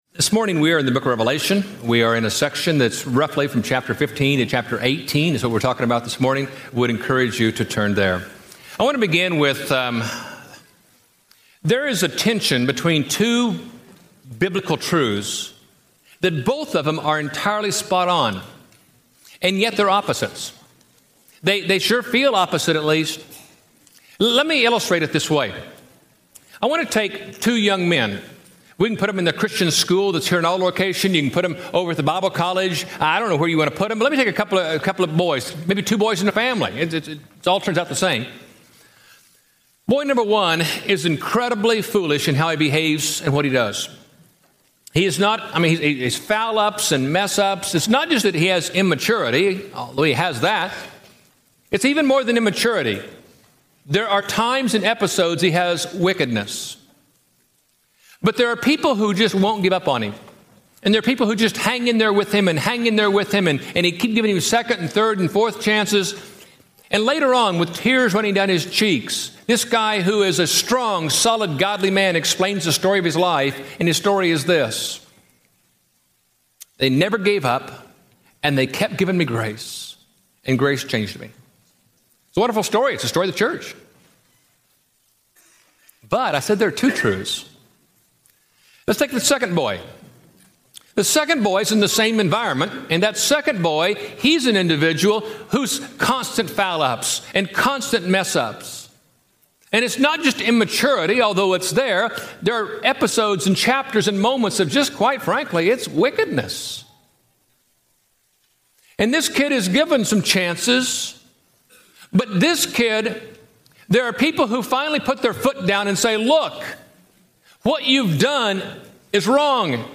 And the Last Gavel Falls Preached at College Heights Christian Church May 20, 2007 Series: Living at Peace in a World Falling to Pieces Scripture: Revelation 15-18 Audio